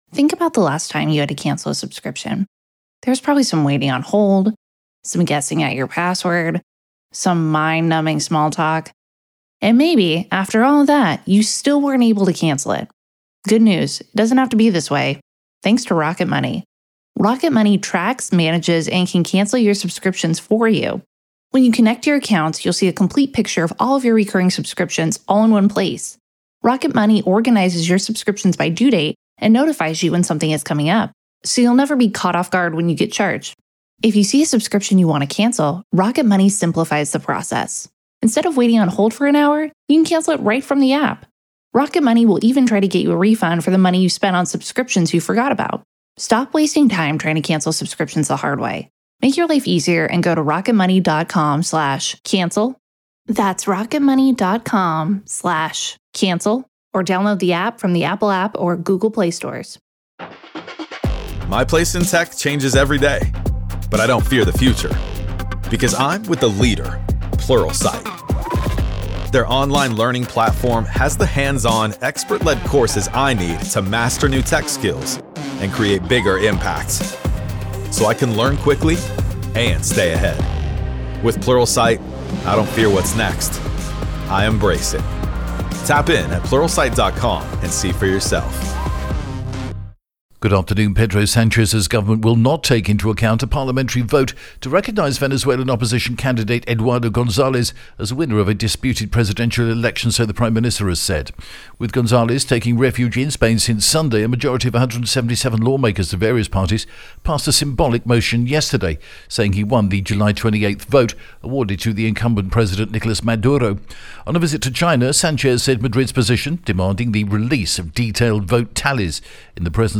The latest Spanish news headlines in English: 12th September 2024